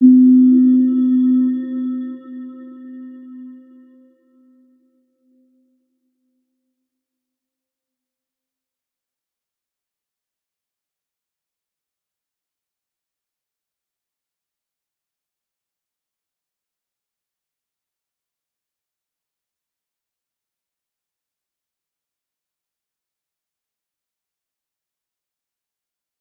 Round-Bell-C4-f.wav